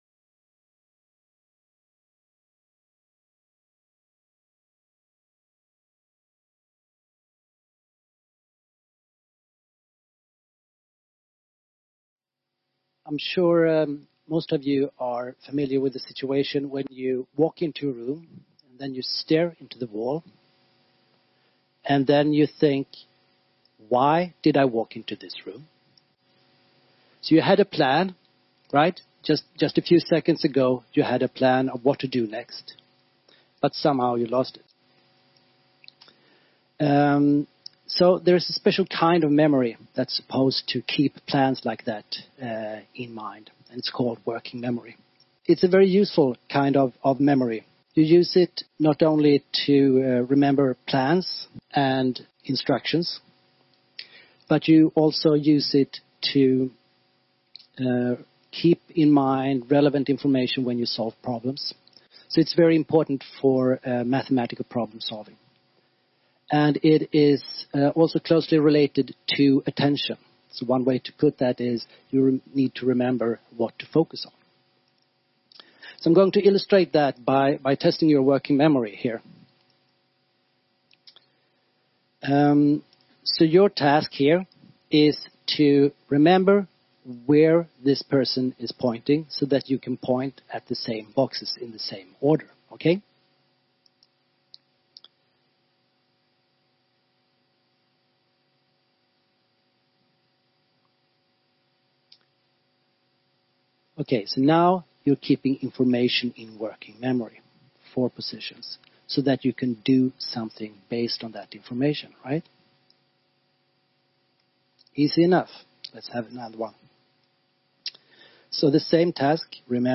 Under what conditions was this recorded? TEDxNorrköping